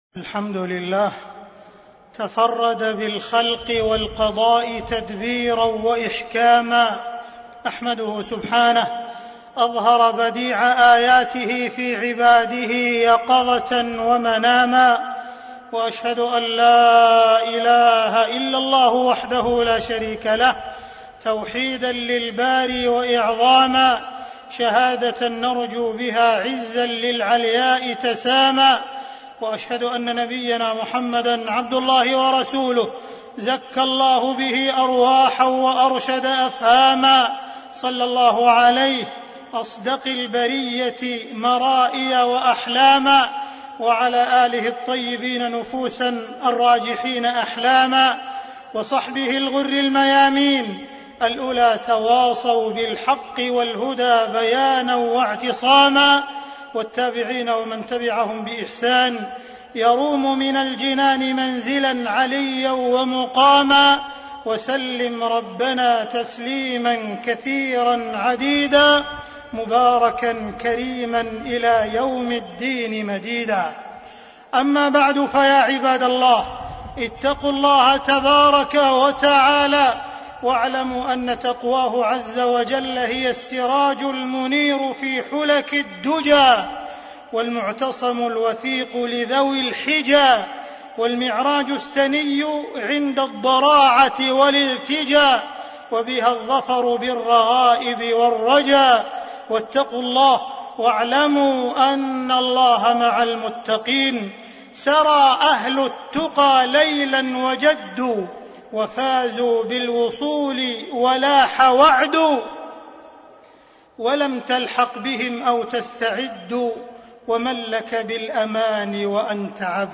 تاريخ النشر ١٩ صفر ١٤٣٣ هـ المكان: المسجد الحرام الشيخ: معالي الشيخ أ.د. عبدالرحمن بن عبدالعزيز السديس معالي الشيخ أ.د. عبدالرحمن بن عبدالعزيز السديس الرؤى والأحلام في ضوء الكتاب والسنة